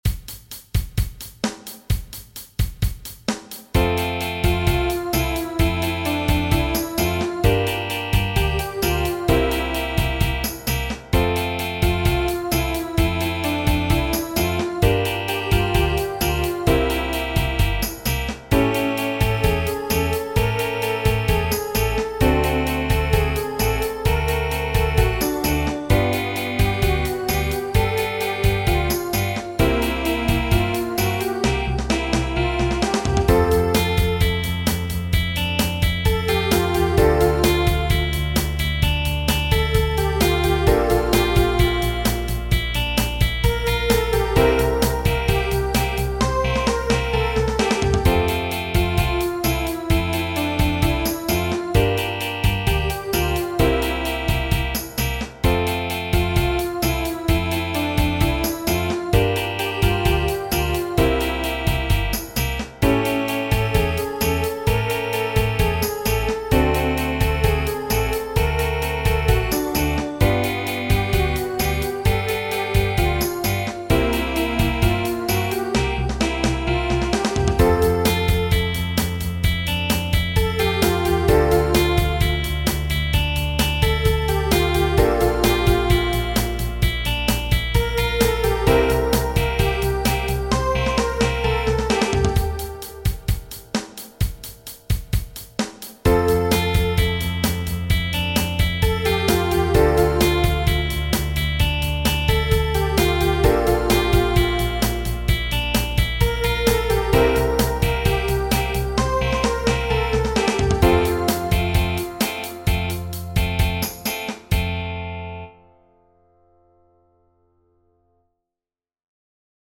Genere: Moderne